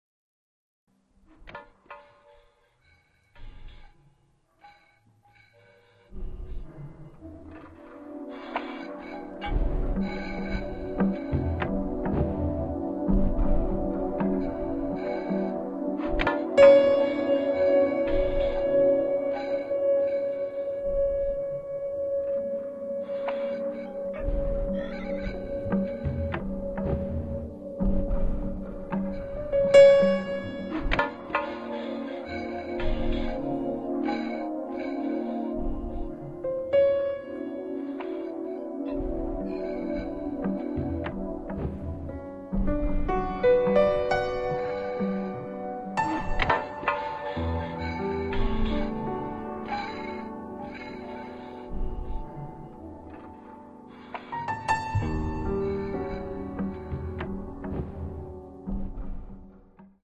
pianoforte, sintetizzatore, samplers, live electronics
contrabbasso, basso elettrico
batteria, percussioni